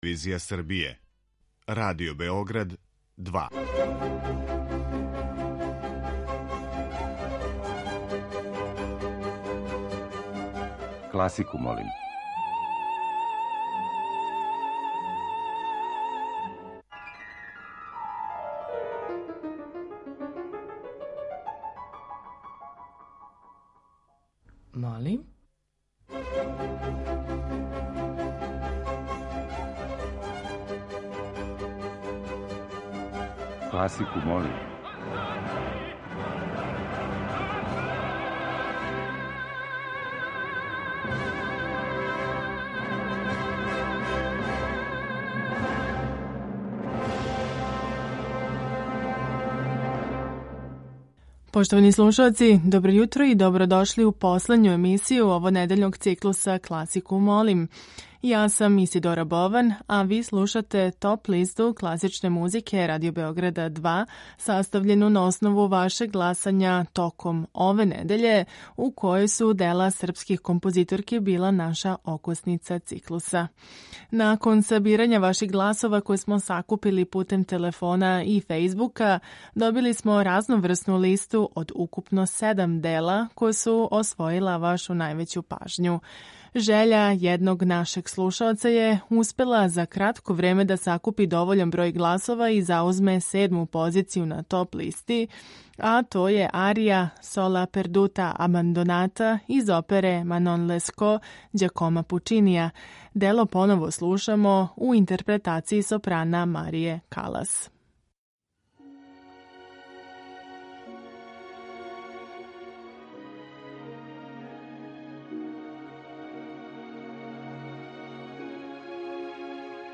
Топ-листа класичне музике